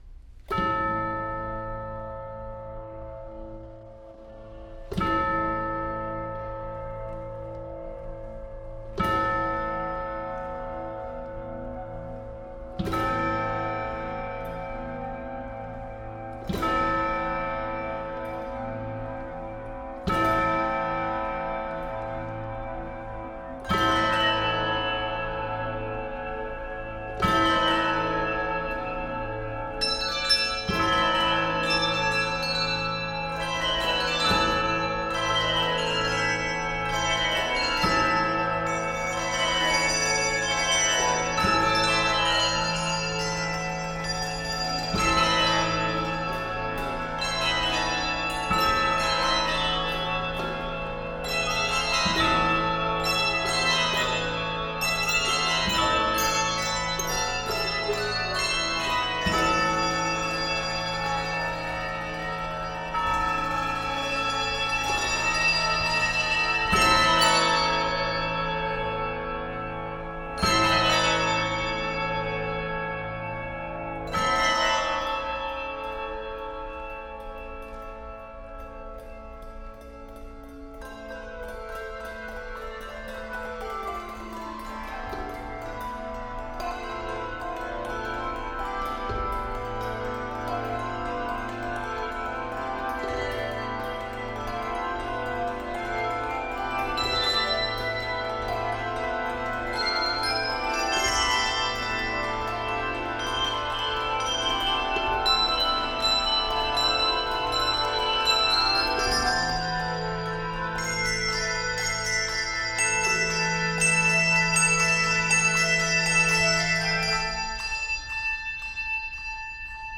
handbells
A harshly dissonant opening yields to a gentler theme